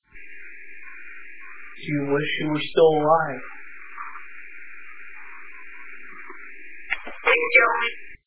Listen after the count to three when there are three definite knocks in response to the request!